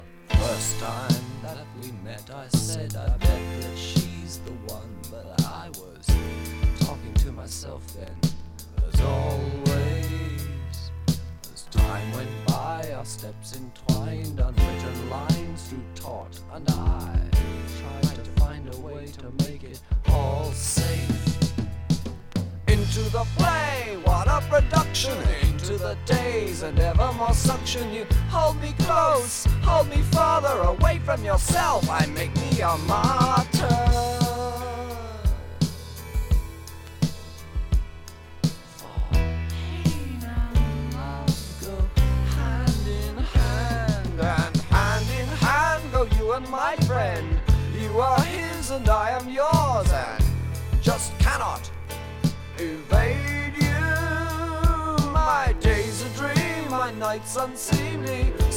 アルバム全体に、ナイーヴな雰囲気が漂った作品。